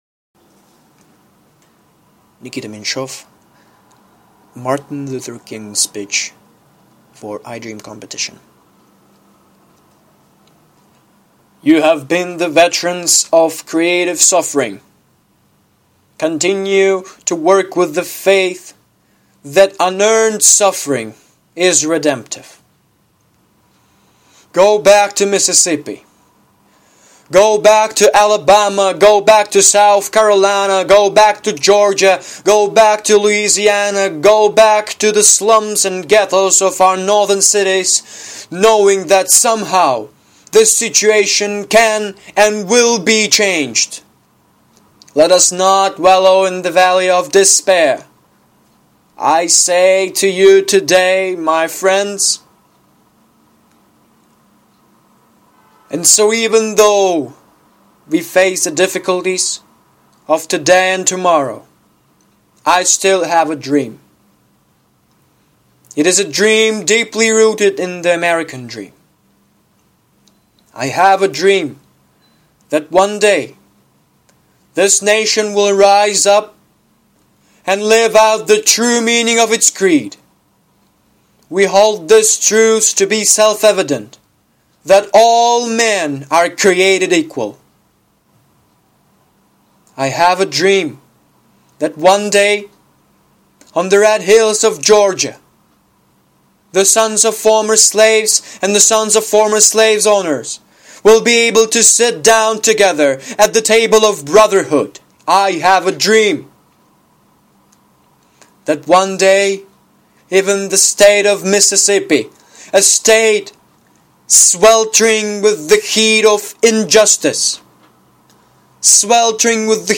Аудиофайл представлен на конкурс "iDream" в честь 50-летия речи Мартина Лютера Кинга "У меня есть мечта". Для участия в конкурсе необходимо было записать отрывок из речи Мартина Лютера Кинга «У меня есть мечта» на кыргызском, русском или английском языке.